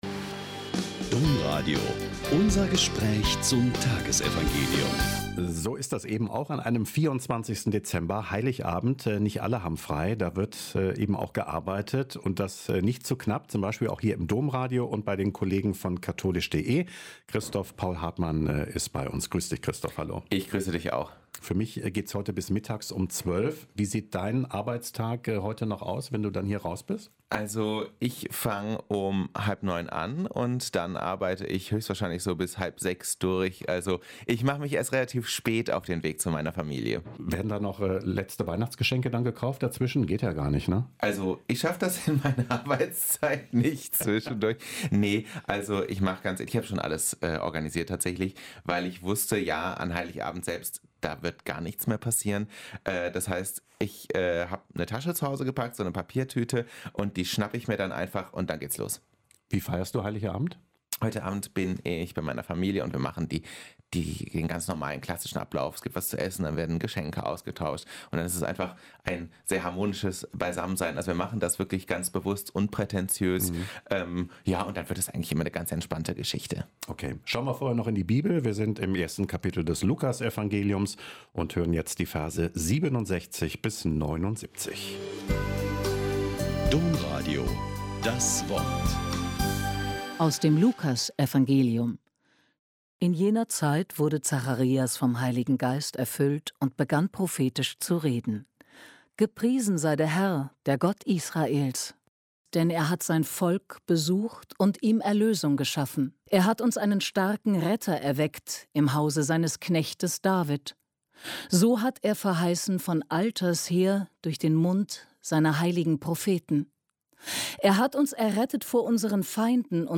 Lk 1,67-79 - Gespräch